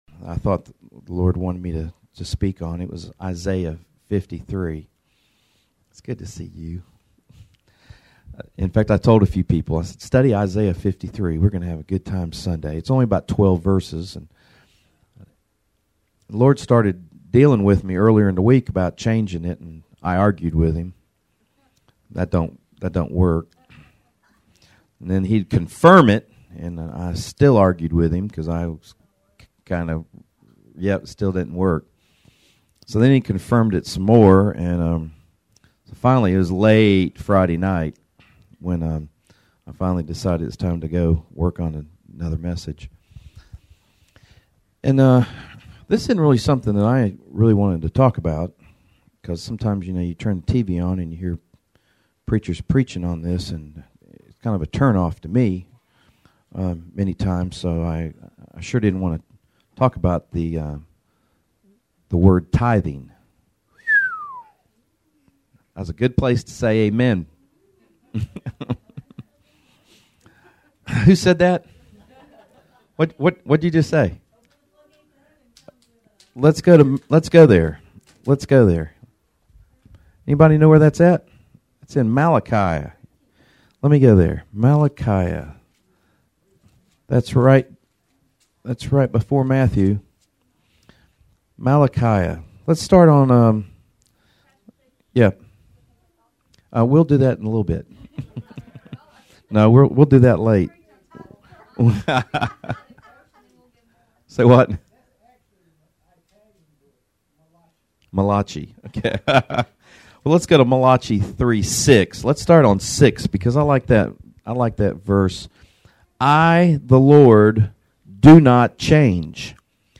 Series: Sermons